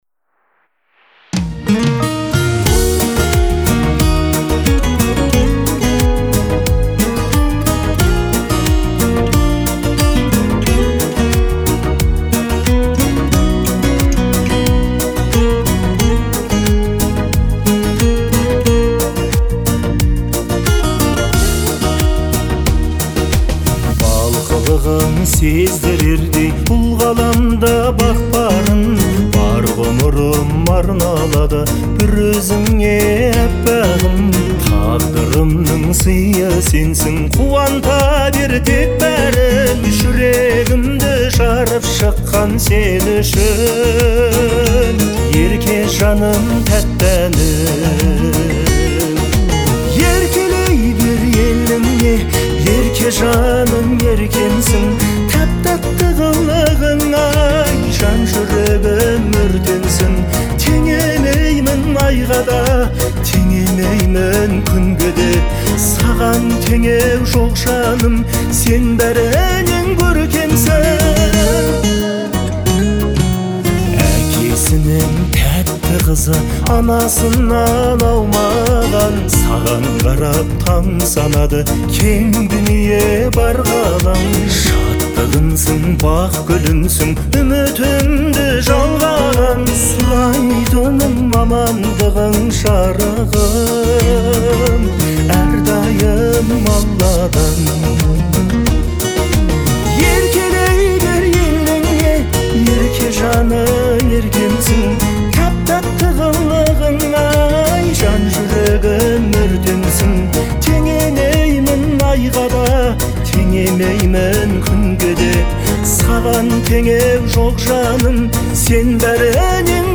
это трогательная песня в жанре казахского поп-фолка